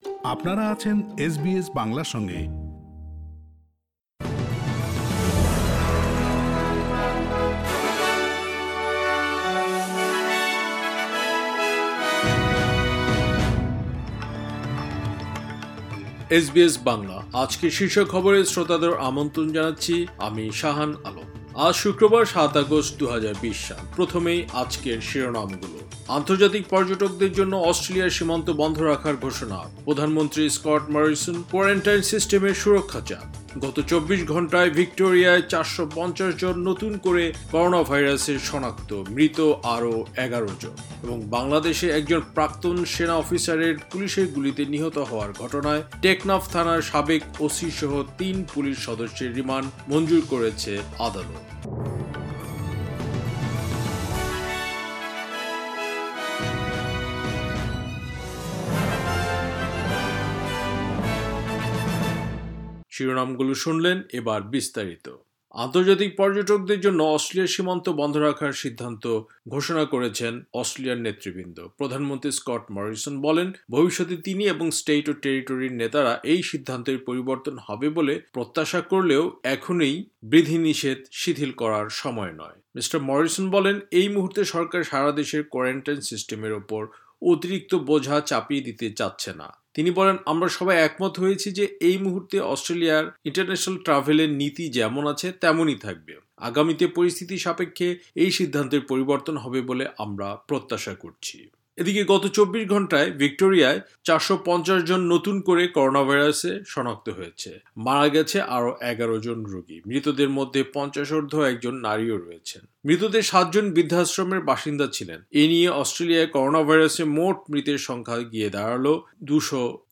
এসবিএস বাংলা শীর্ষ খবর, ৭ আগস্ট, ২০২০